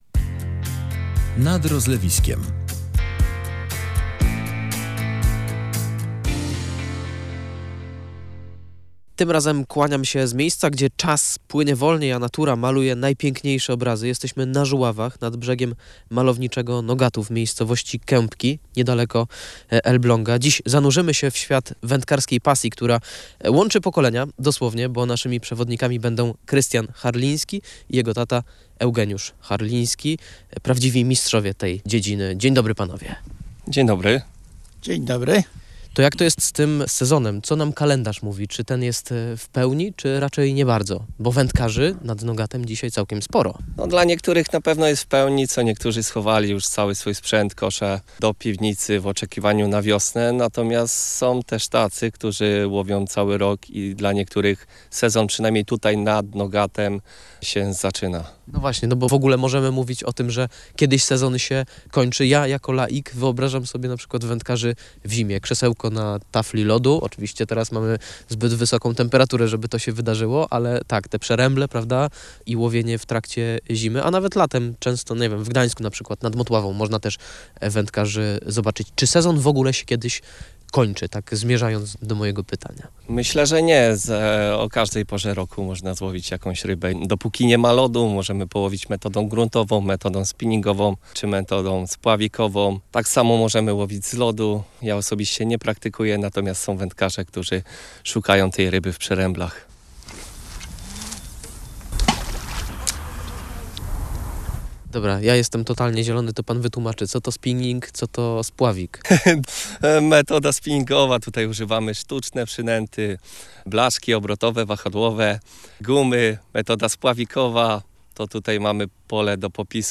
Tu czas płynie wolniej. Spotkaliśmy się z wędkarzami znad Nogatu